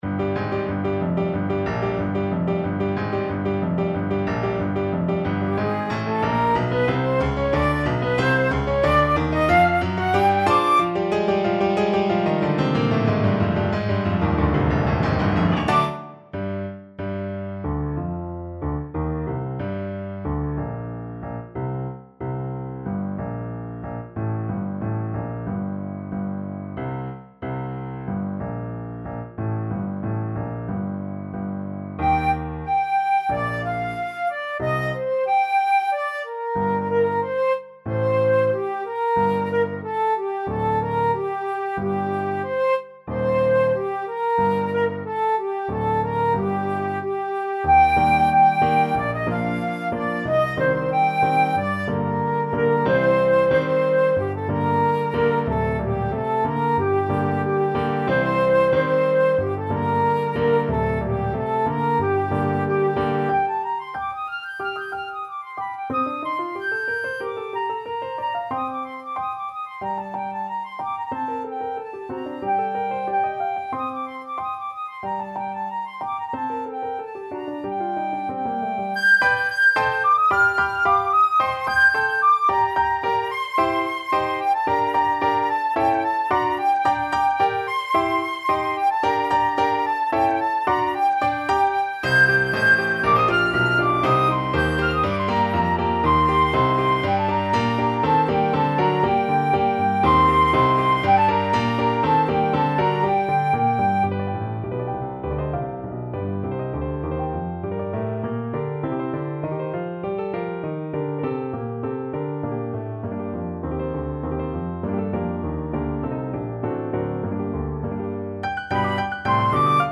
Flute
2/4 (View more 2/4 Music)
G minor (Sounding Pitch) (View more G minor Music for Flute )
Allegro =92 (View more music marked Allegro)
Classical (View more Classical Flute Music)